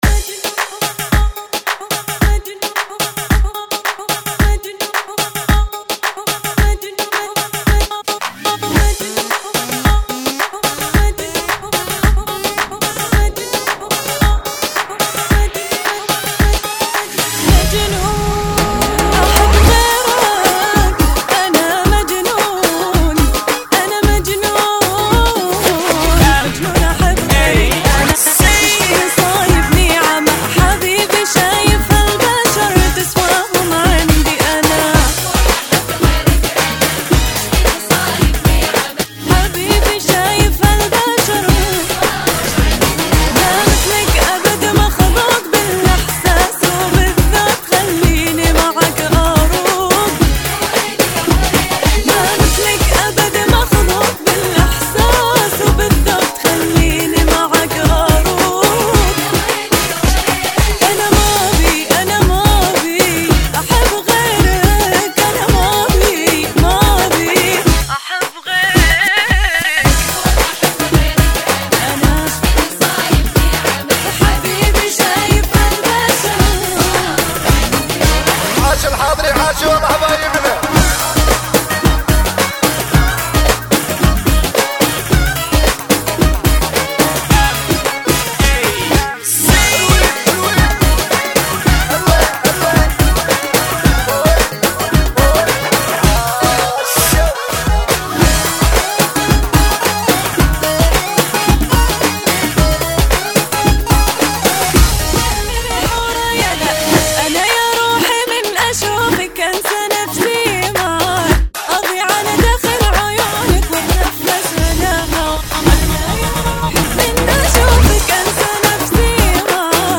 110 bpm
Funky